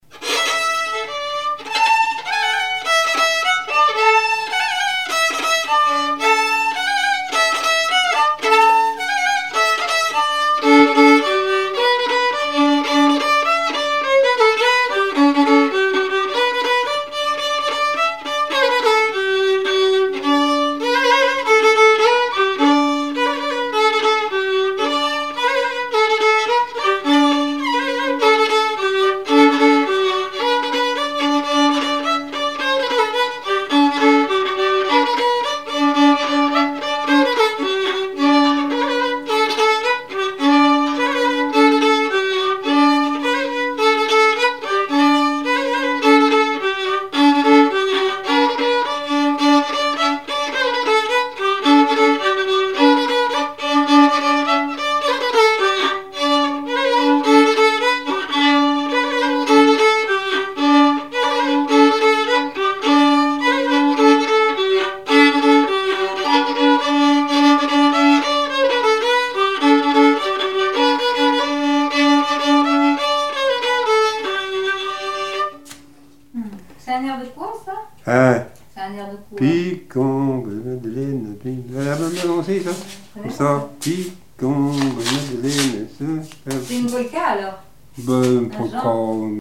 Polka
Chants brefs - A danser
polka piquée
Témoignages et chansons
Pièce musicale inédite